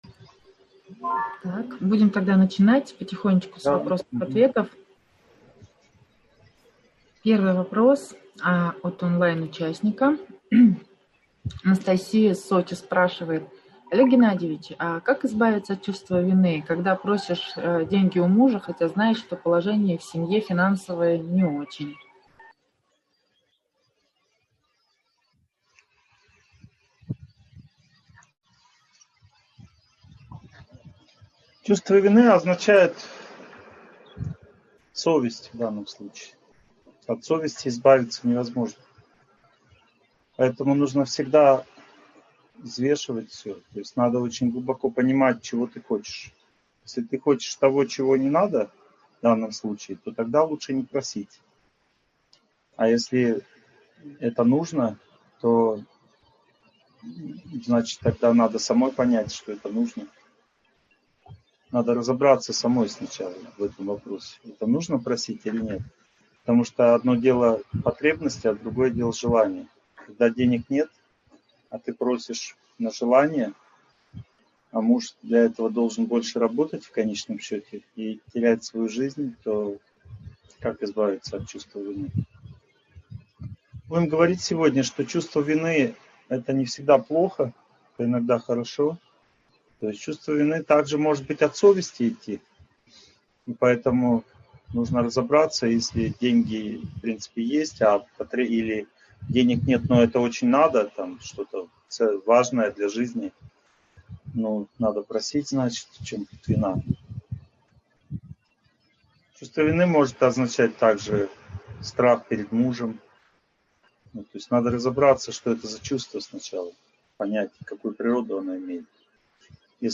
Чувство вины, самобичевание, апатия. Как справиться? (онлайн-семинар, 2021)